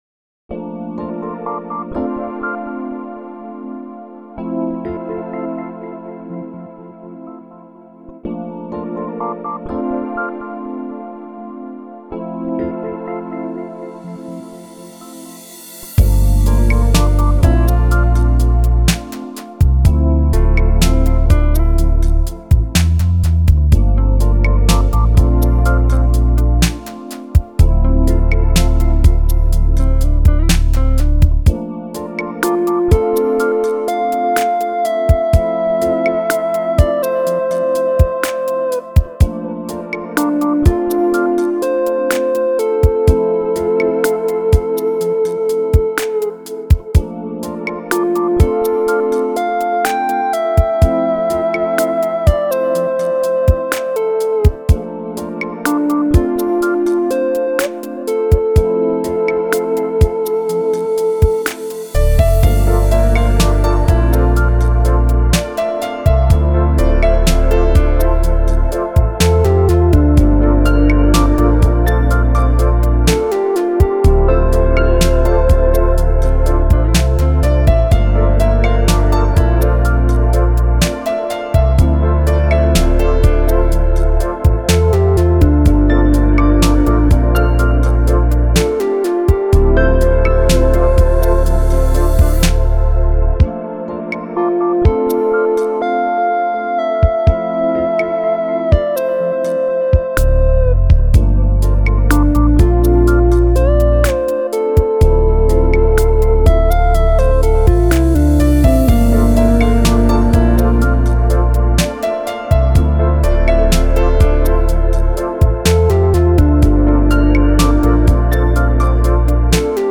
Genre: Smooth Jazz, Chillout, Longe